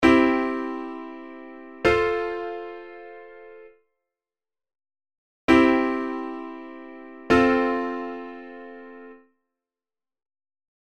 例として、コード進行CFGCのC⇒Fの部分に注目します。
構成音Cが共通しているので、画像の右側のように、コードFの時の構成音Cは、コードCの時の構成音Cと同じところを弾きます。